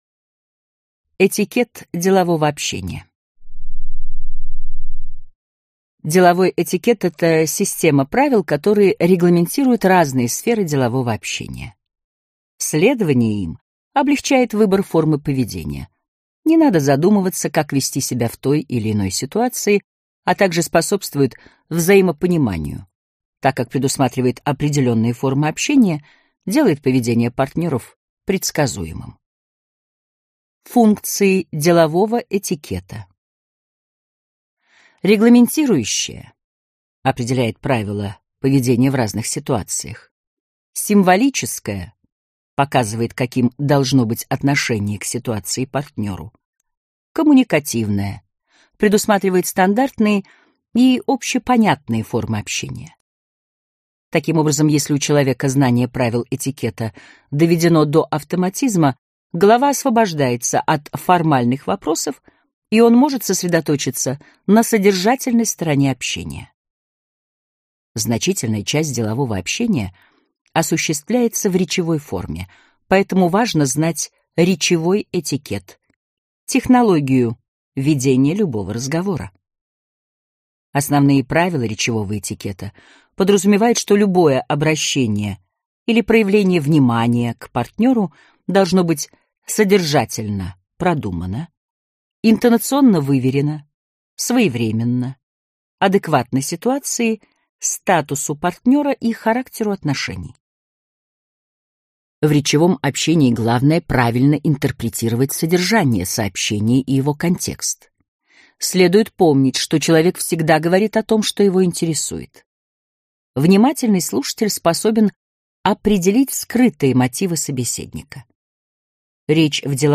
Аудиокнига Деловое общение. Формы и правила | Библиотека аудиокниг